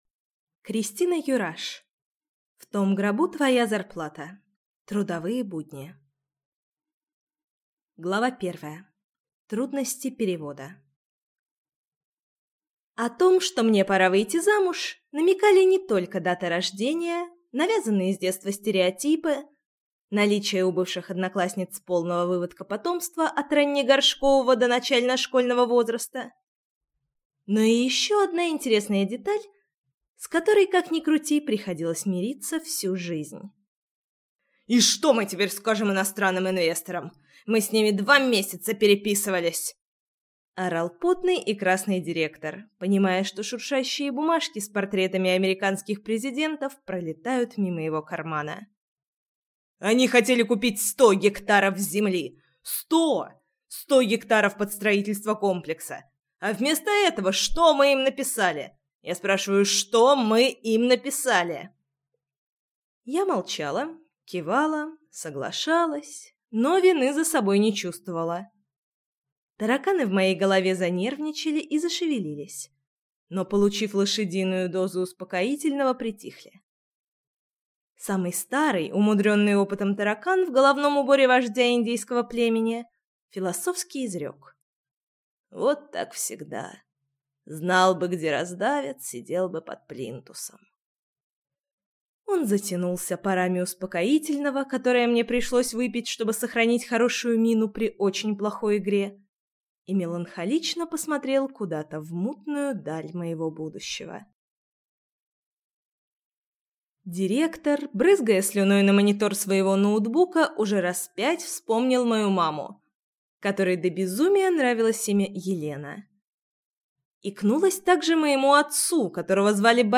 Аудиокнига В том гробу твоя зарплата. Трудовые будни | Библиотека аудиокниг